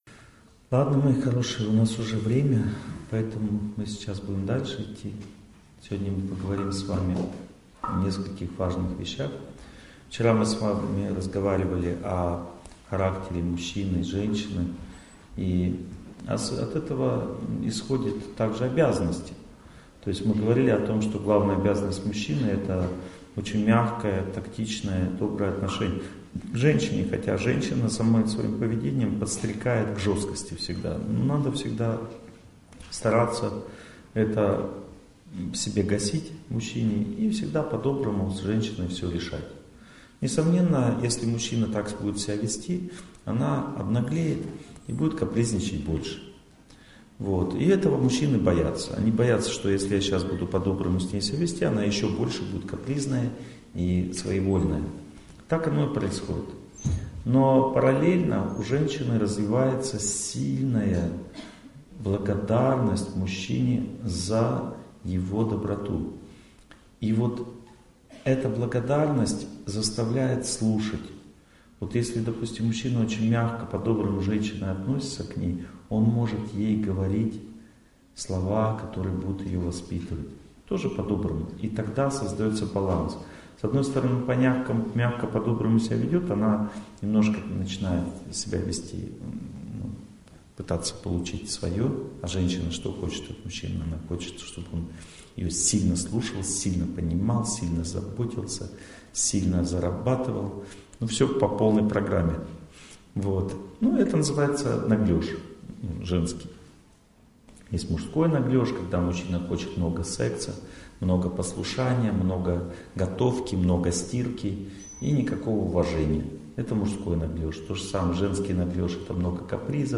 Аудиокнига Как разобраться в своих отношениях. Часть 2 | Библиотека аудиокниг